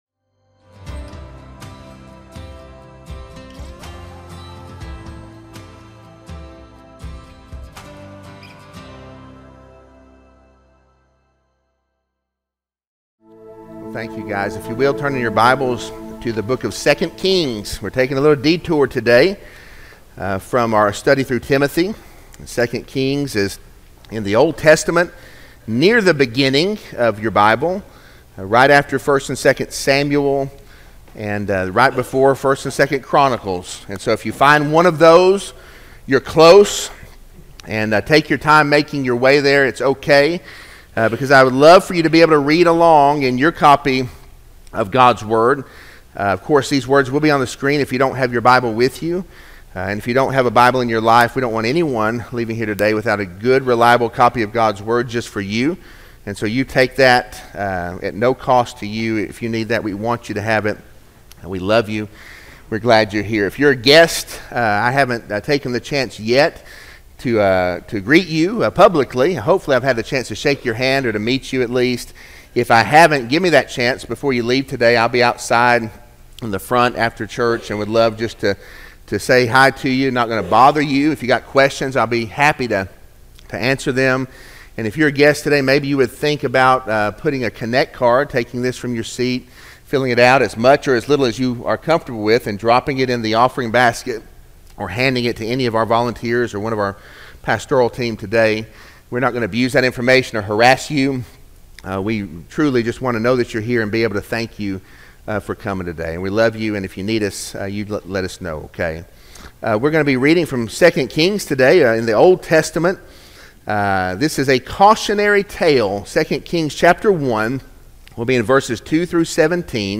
Baptist Sermons Podcaster